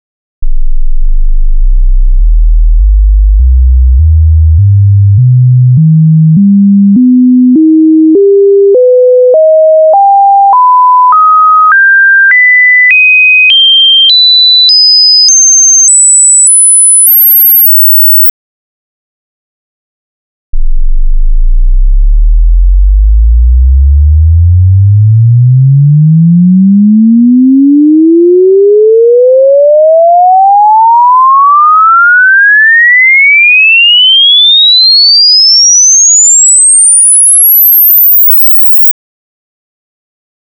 テストトーン(44.1kHz 16bit wav)を作りました。(かなりうるさいので注意)詳しい作り方は省略しますが、このテストトーンは、20Hz〜20kHzまでのサイン波を、ログスケールでステップスウィープ、次は普通のスウィープの順で鳴らせています。波形の振幅は16bit最大の音量(-0dB)です。
testtone.wav